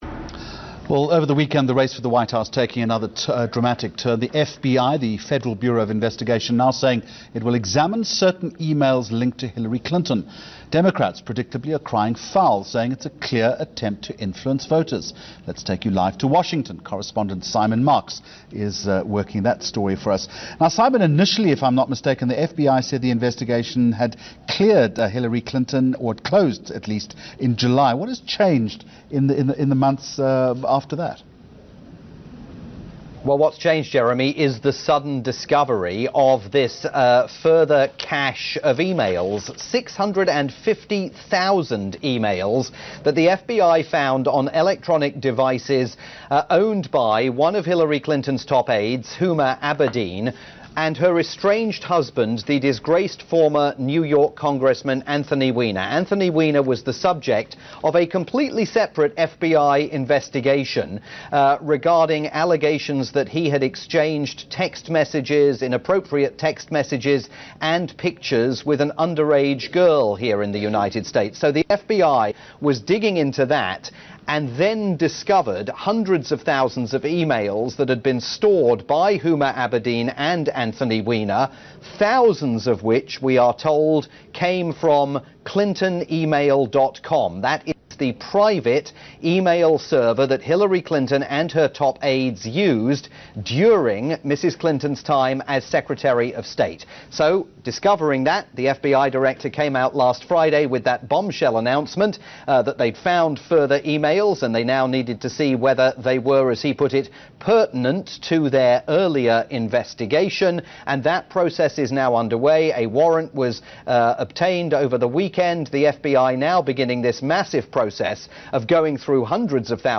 live report via South Africa's leading TV news channel ENCA